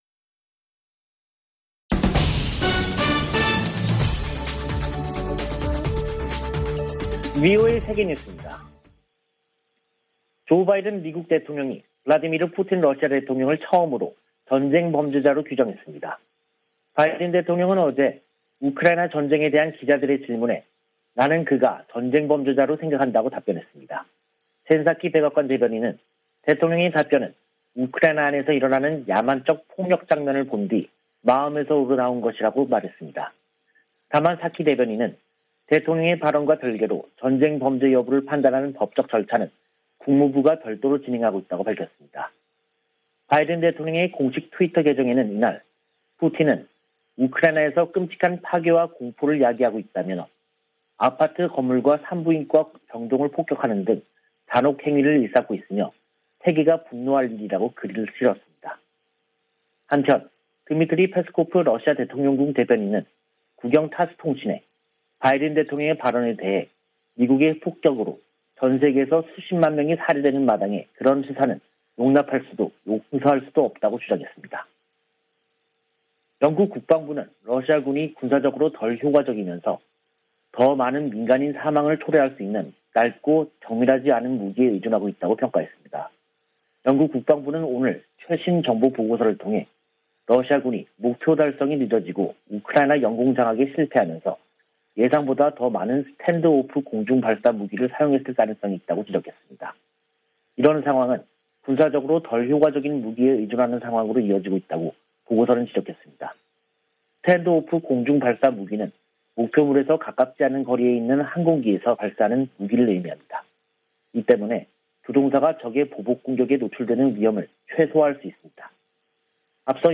VOA 한국어 간판 뉴스 프로그램 '뉴스 투데이', 2022년 3월 17일 2부 방송입니다. 미군 당국은 한반도의 어떤 위기에도 대응 준비가 돼 있다며, 억지력 기초는 준비태세라고 강조했습니다. 북한은 16일 탄도미사일 발사 실패에 침묵하고 있습니다. 미국의 전문가들은 북한의 지속적인 미사일 발사에 미한이 억지력 강화에 주력하면서 한반도 긴장이 고조될 것이라고 관측했습니다.